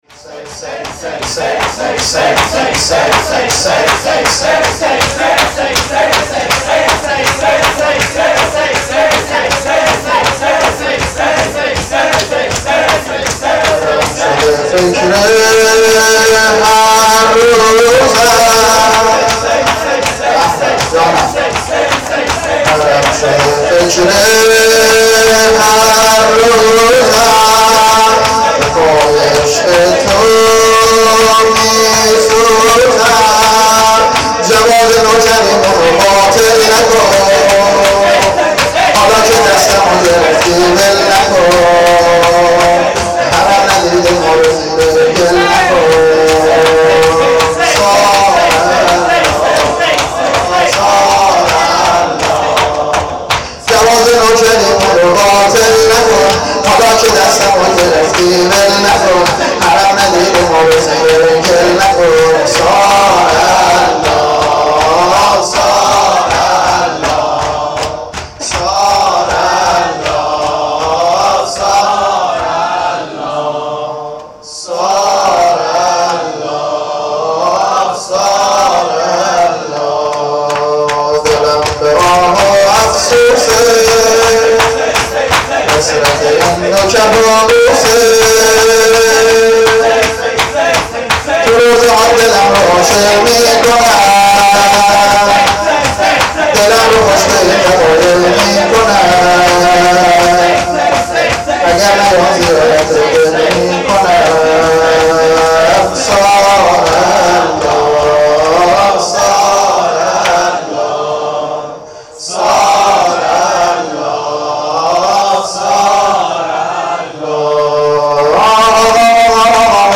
شور-طوفانی-شب-هفت.mp3